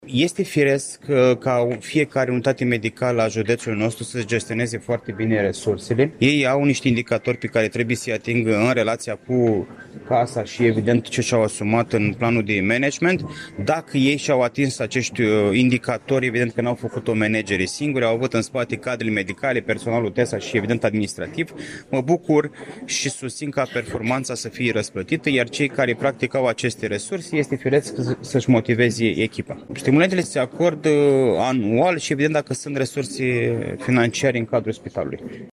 Președintele Consiliului Județean Iași, Costel Alexe a declarat că modificările legislative în domeniu prevăd posibilitatea acordării acestor stimulente financiar.